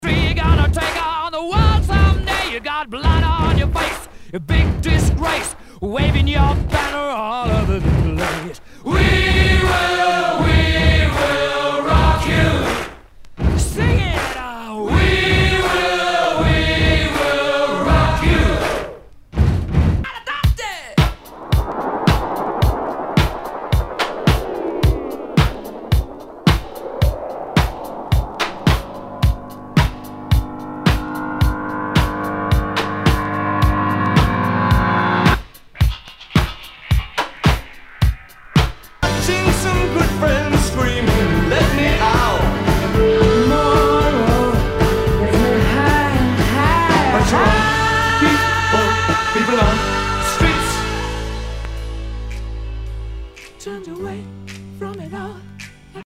ROCK/POPS/INDIE